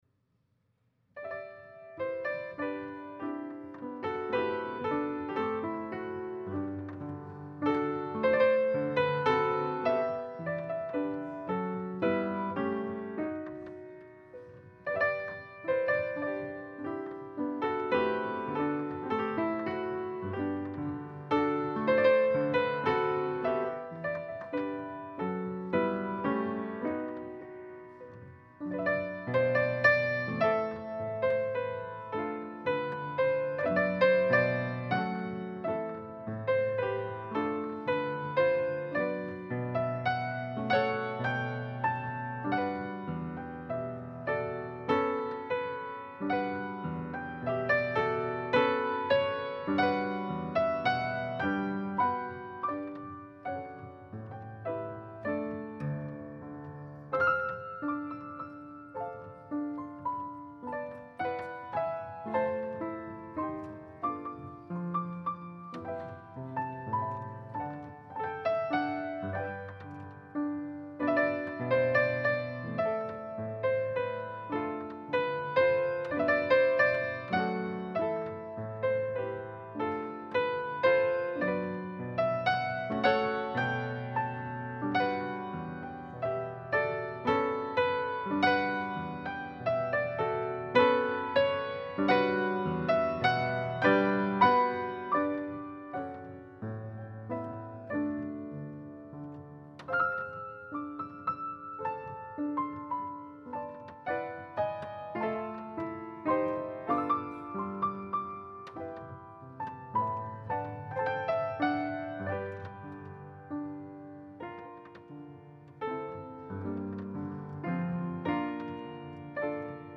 2026 Good Friday Service